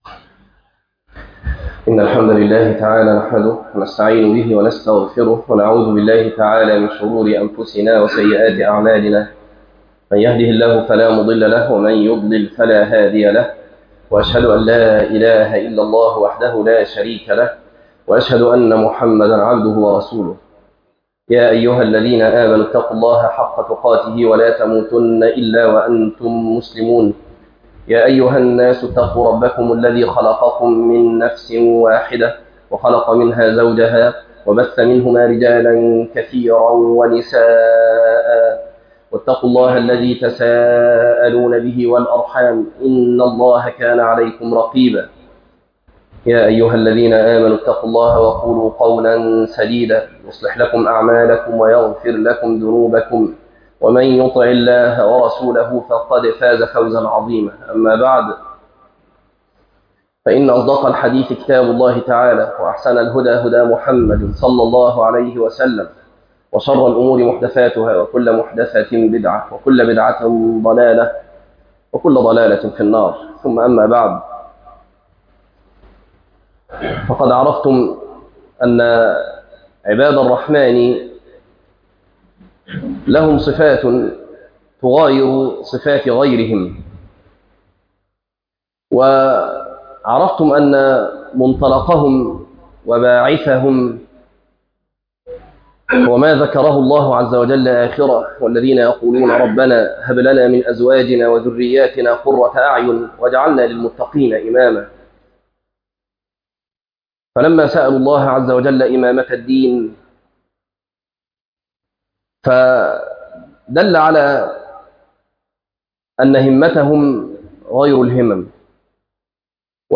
تفاصيل المادة عنوان المادة ٣- وعباد ٱلرحمـن - خطبة تاريخ التحميل الجمعة 10 ابريل 2026 مـ حجم المادة غير معروف عدد الزيارات 16 زيارة عدد مرات الحفظ 10 مرة إستماع المادة حفظ المادة اضف تعليقك أرسل لصديق